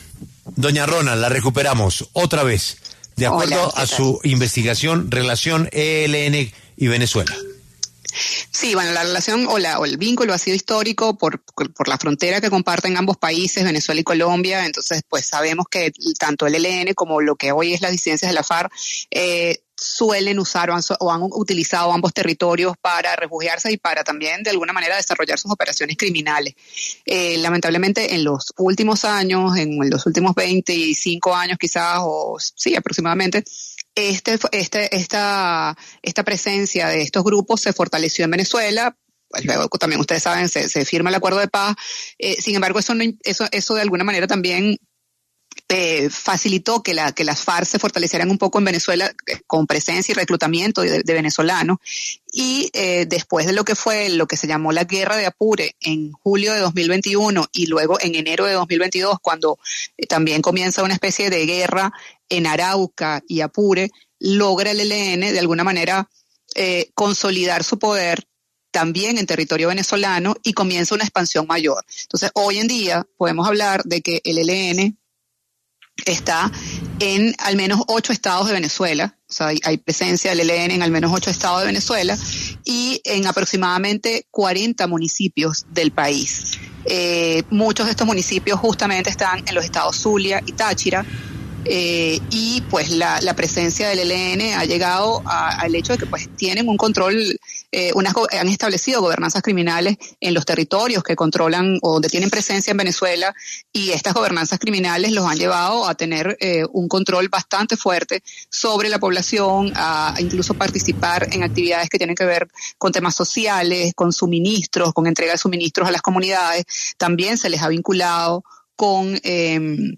Negociaciones de paz con el ELN